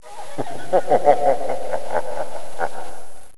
LACHEN.wav